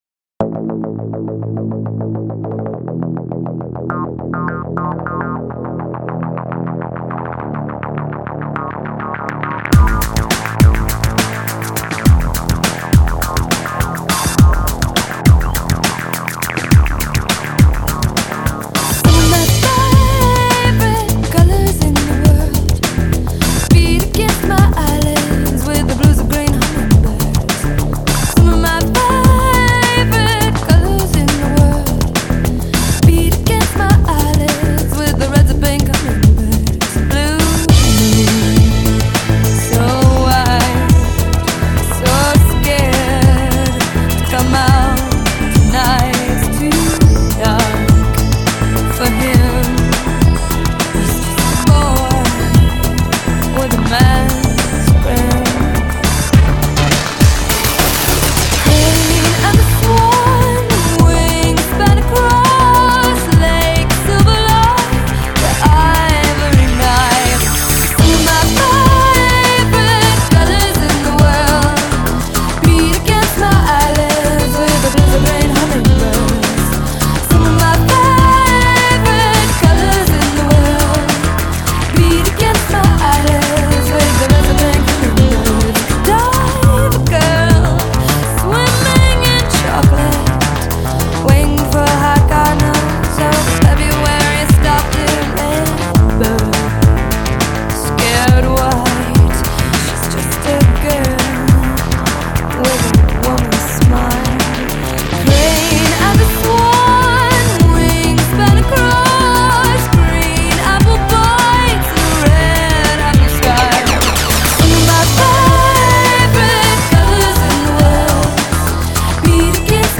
home studio in Nashville
This is a bright shiny slice of synthpop.